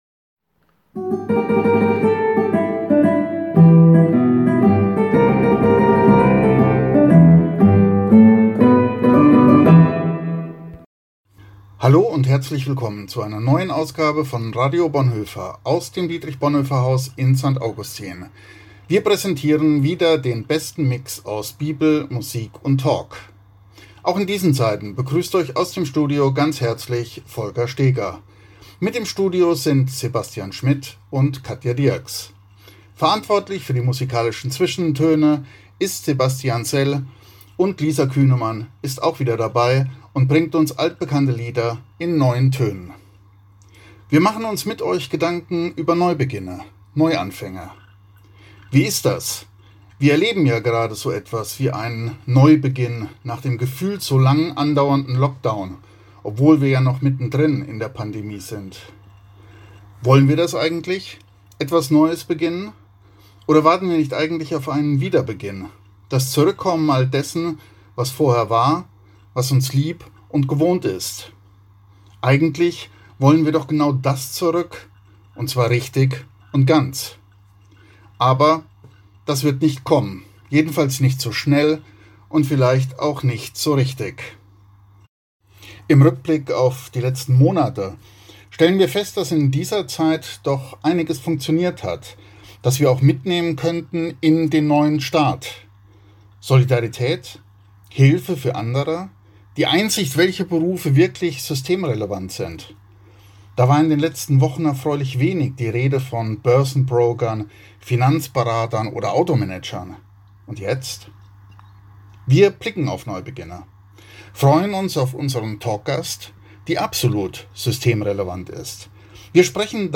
Radio Bonhoeffer – Hier trifft Gottesdienst und Gebet auf Talk und Musik.